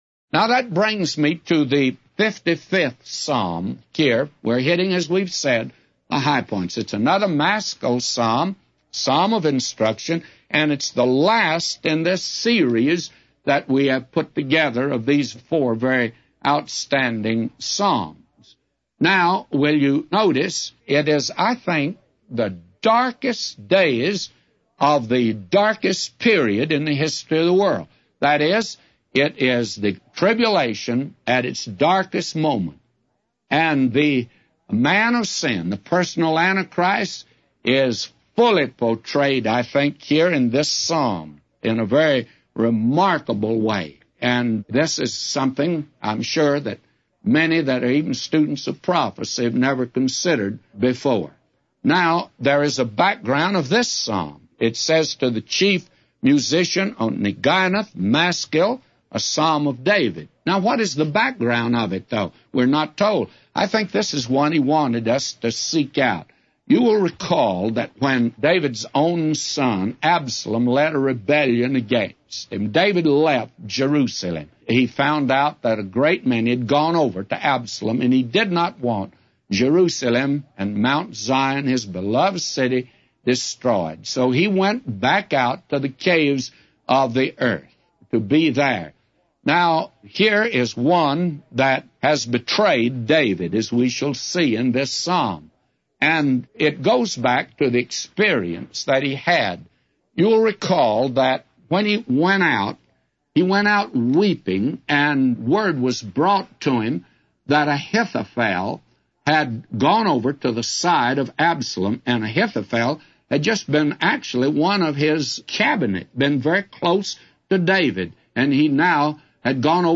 A Commentary By J Vernon MCgee For Psalms 55:1-999